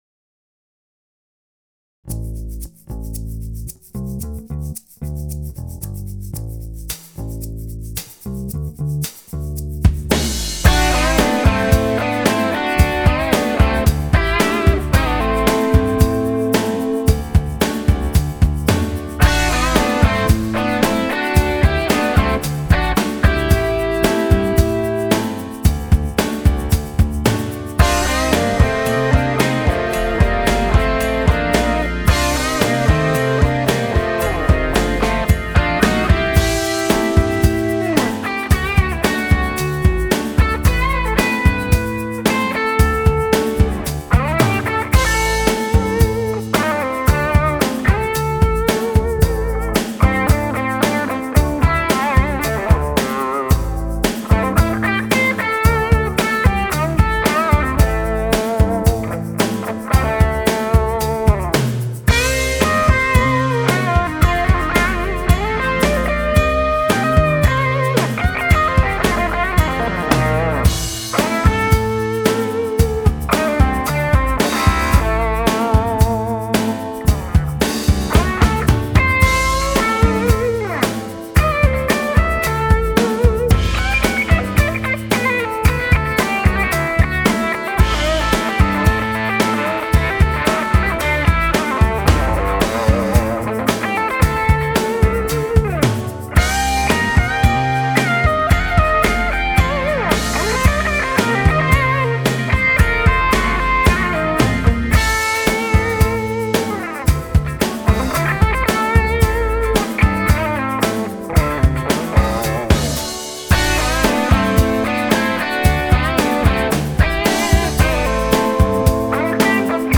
MP3 Audio Clip  Jazz / Rock West, Clapton, Felder Les Paul R0 Leads, Strat Funk Rhythm, 5E3-Eldo, B12Q None
Guitars, Bass, Programming
Signal chain: DIY Teletronix La2a and Pultec EQP-1a, Revolution Redd.47 Preamp, Purple Audio MC76, Altec 438a. Microphones: Vintage Shure Unidyne III, Sennheiser e906 (close) and AKG 414 (room). Speakers: Speed Shop A12Q, Celestion Blue, G12M Heritage. Amps: Various RecProAudio Tweed Deluxe P2P and Studio-Series with NOS Tubes: RCA 6V6GT, RCA 12AX7, RCA 12AY7, RCA 5Y3.